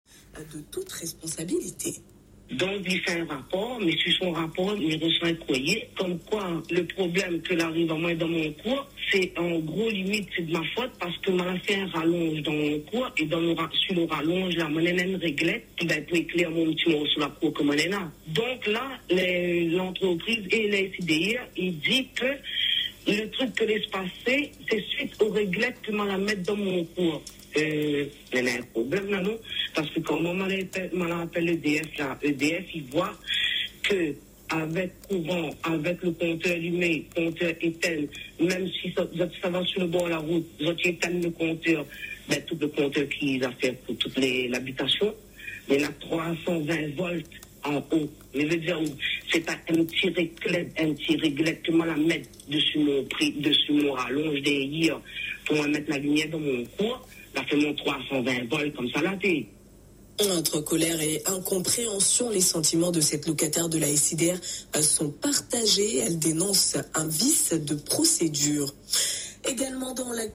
La femme conteste cette version et dénonce la situation. Elle a témoigné au micro de notre radio :